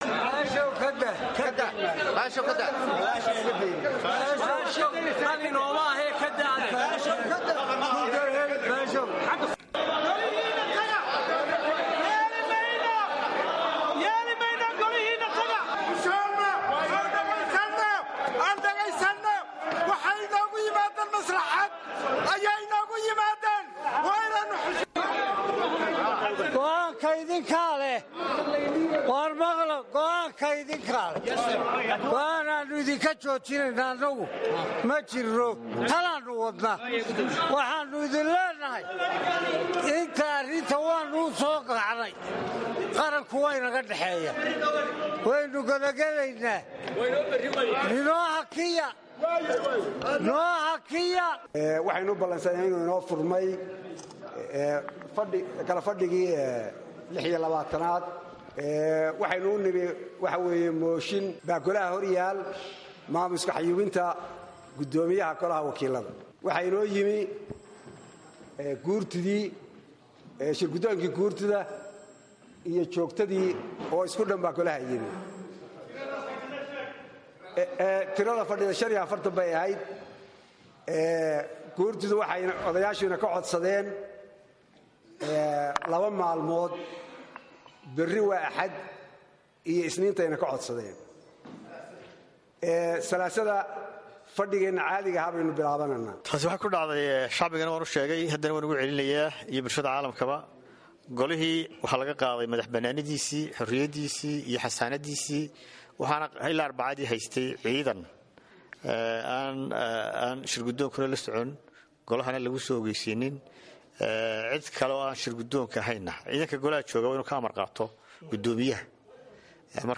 DHAGEYSO: Dooddii Maanta ka dhacday Aqalka Wakiillada Somaliland
Doddii_Baarlamaanka_Somaliland_Hargeysa_HOL.mp2